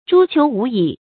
誅求無已 注音： ㄓㄨ ㄑㄧㄡˊ ㄨˊ ㄧˇ 讀音讀法： 意思解釋： 勒索榨取；沒有止境。